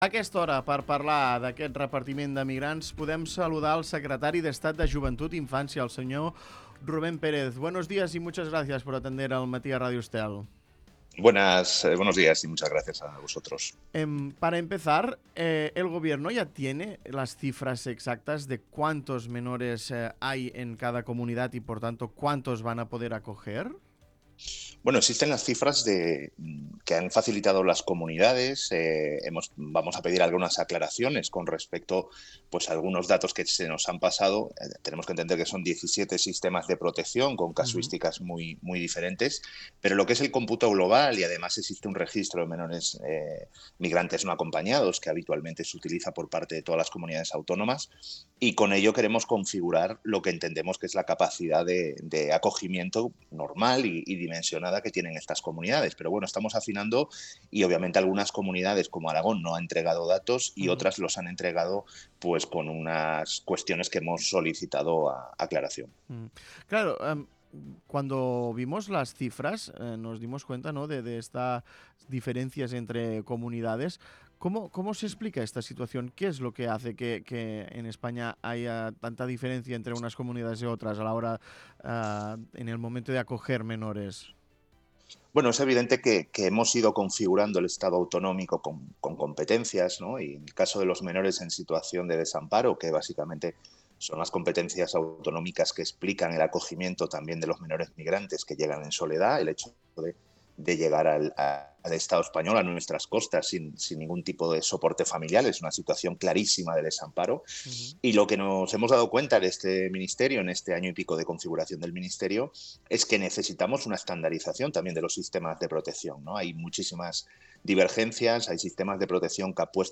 Escolta l'entrevista a Rubén Pérez, secretari d’estat de Joventut i Infància